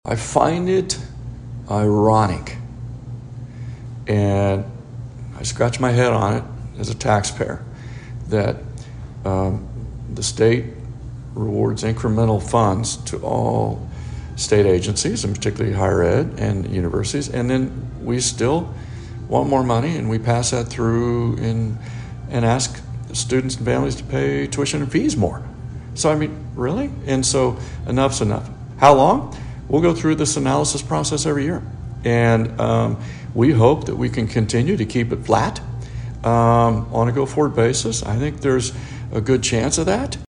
In his interview with KVOE News, Hush again credited partners such as the ESU Foundation, city of Emporia, Lyon County, Kansas lawmakers, alums, donors and foundations for their support